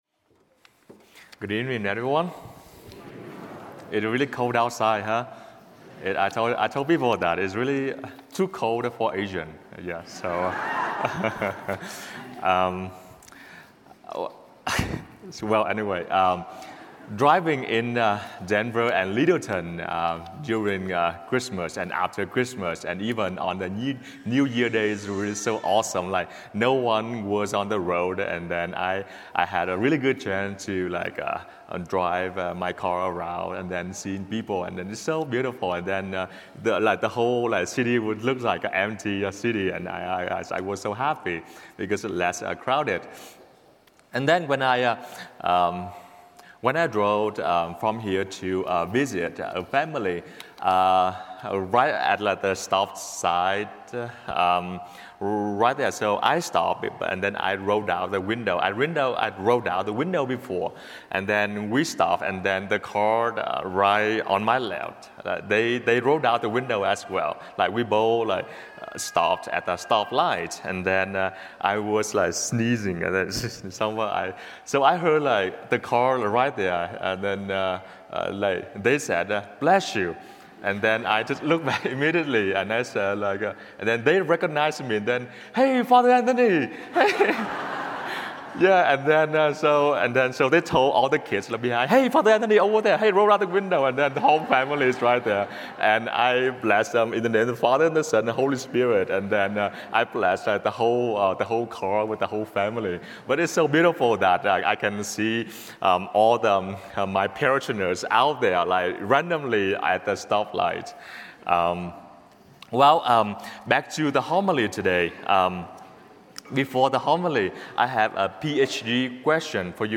Light of the World Catholic Parish